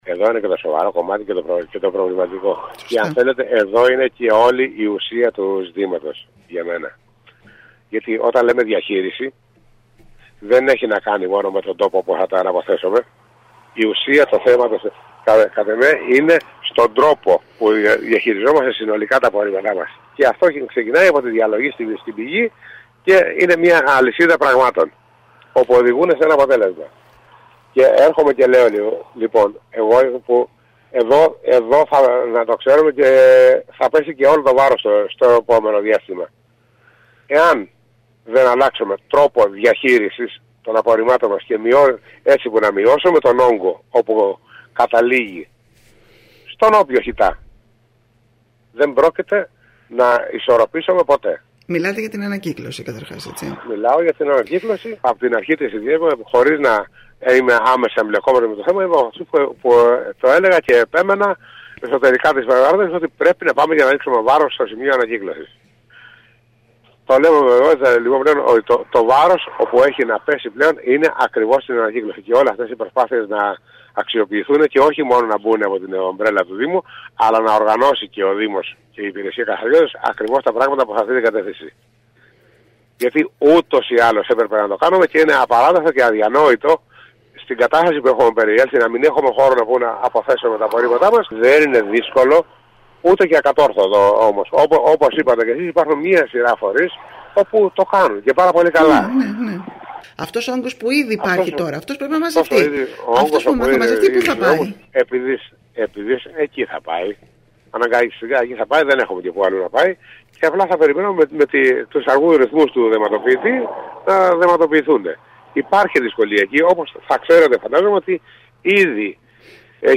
Με μεγάλη δυσκολία εξελίσσεται η αποκομιδή των απορριμμάτων σε όλη την Κέρκυρα ενώ μιλώντας σήμερα στην ΕΡΤ ο νέος αντιδήμαρχος καθαριότητας Σπύρος Καλούδης επεσήμανε ότι αυτή δεν εξαρτάται μόνο από το δήμο αλλά και από άλλους παράγοντες.